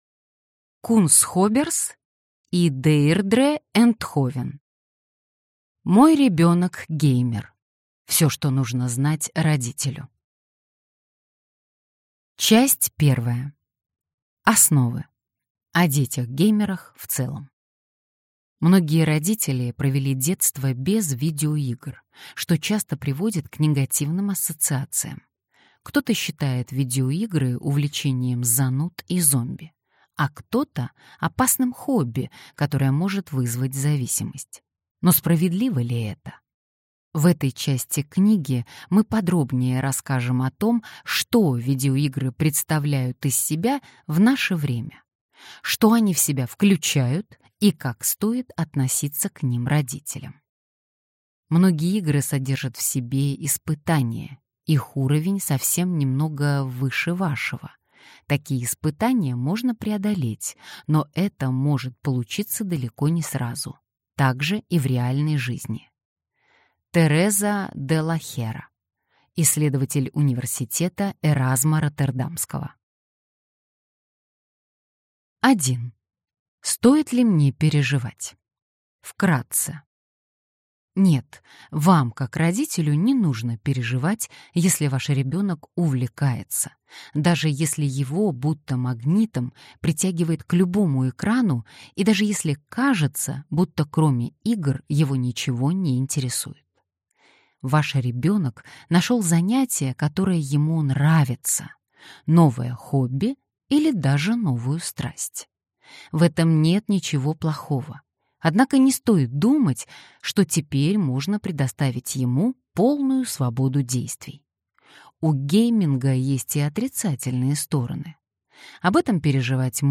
Аудиокнига Мой ребенок – геймер. Всё, что нужно знать родителю | Библиотека аудиокниг